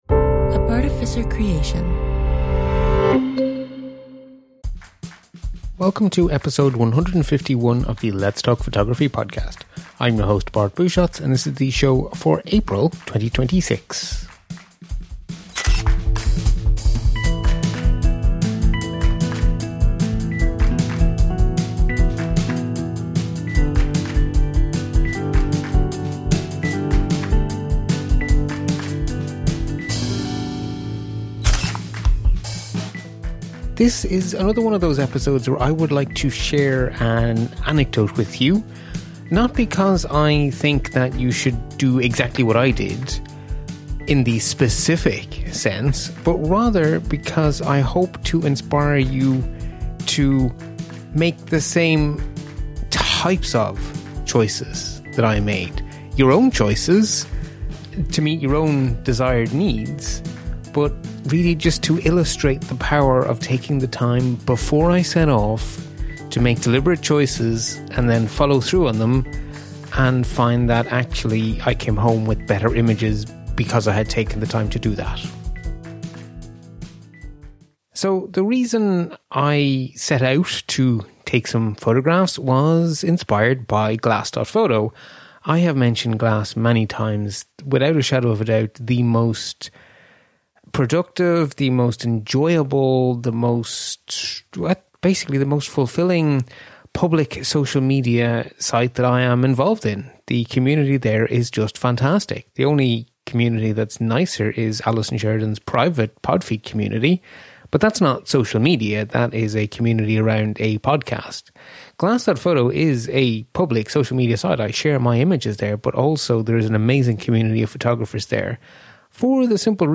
In this solo show